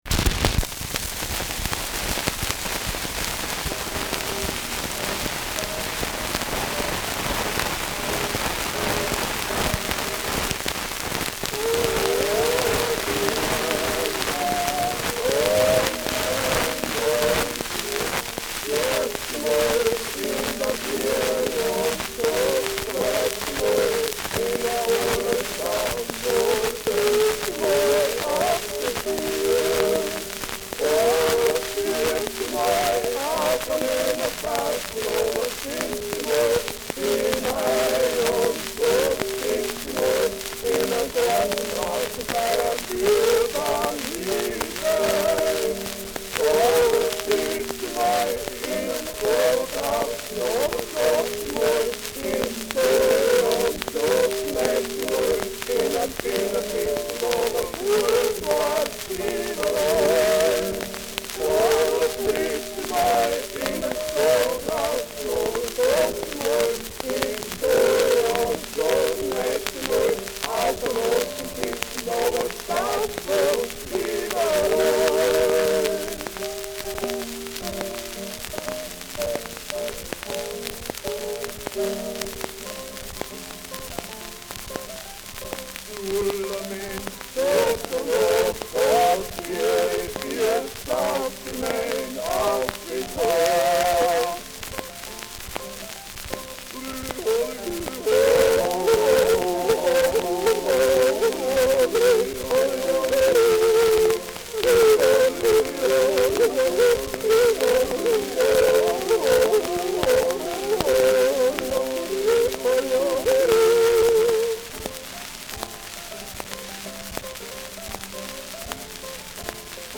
Schellackplatte
Stark abgespielt : Sehr leise : Starkes Grundrauschen : Durchgehend leichtes Knacken : Erhöhter Klirrfaktor
Grausgruber Terzett (Interpretation)